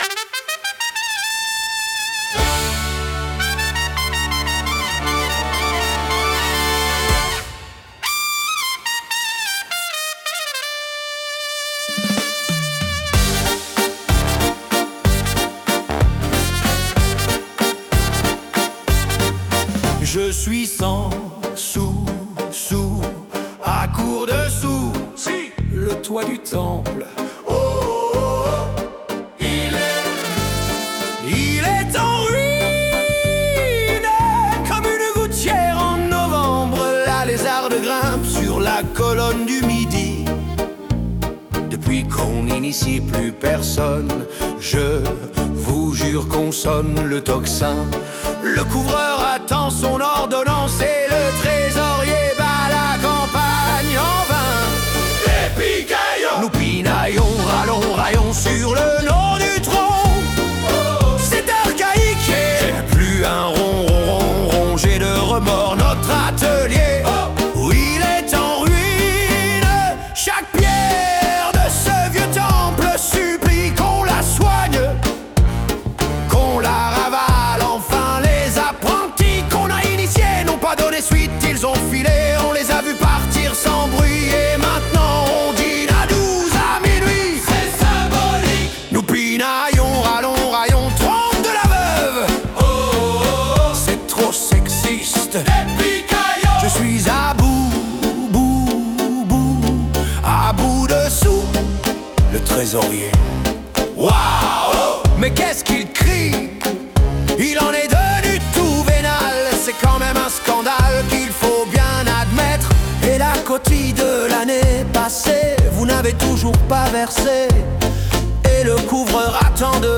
Parodie maçonnique
Personnages : Le Vulnérable — Les Harpagons (chœur)